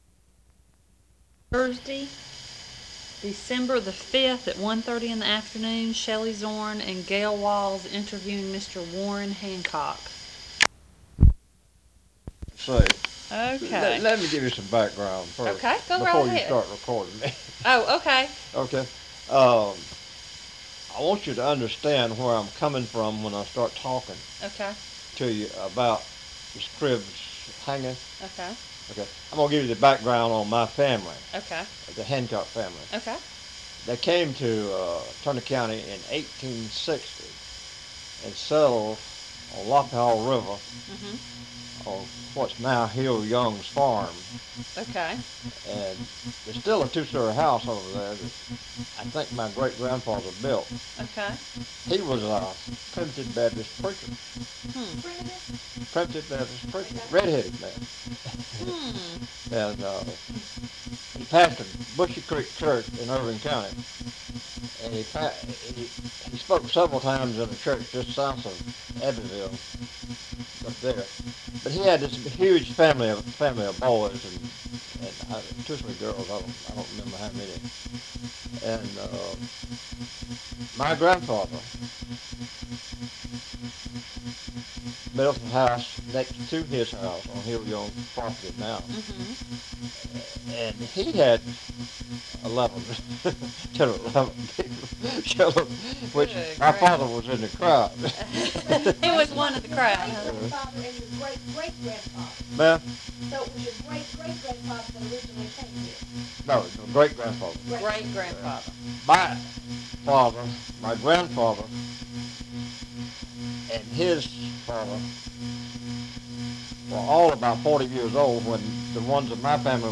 However, the tape is degraded within 4 minutes of the interview.
Oral History Item Type Metadata
Tape